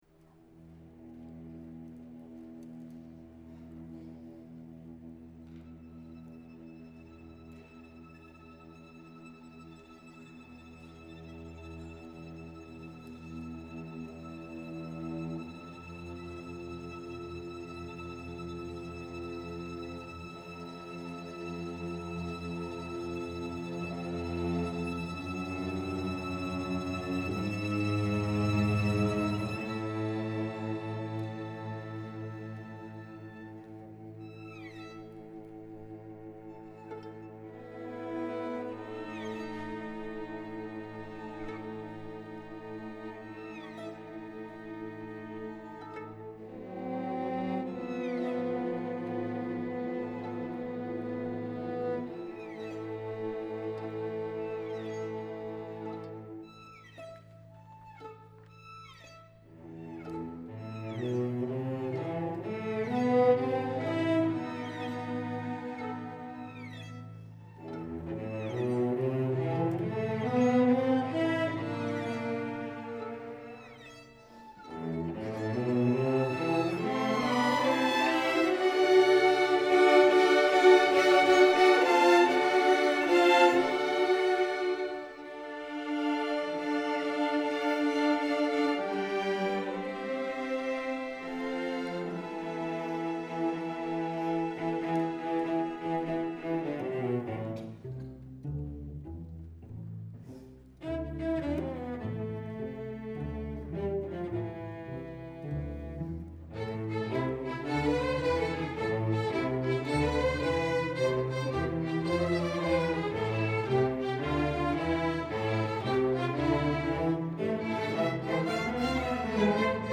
Instrumentation: String Orchestra